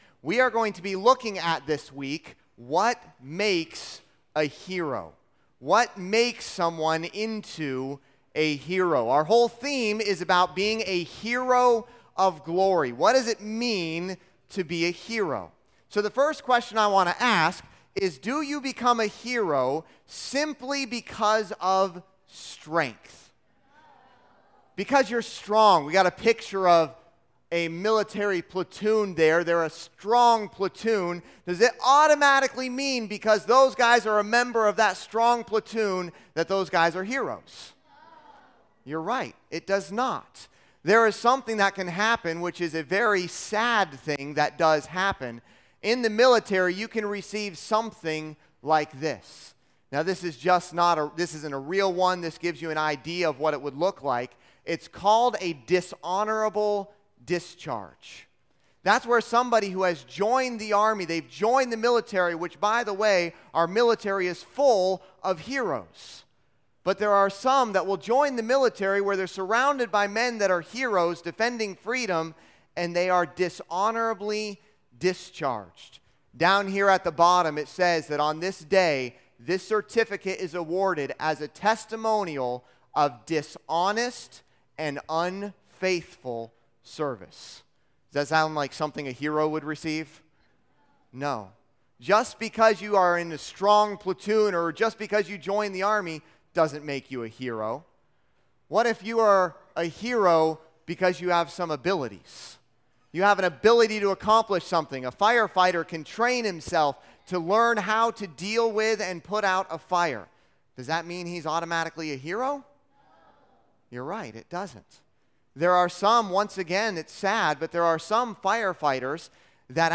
Service Type: Junior Camp